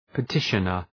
{pə’tıʃənər}